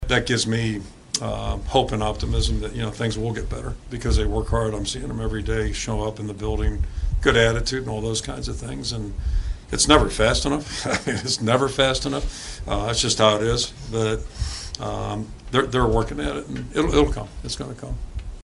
Despite that coach Kirk Ferentz says he saw progress.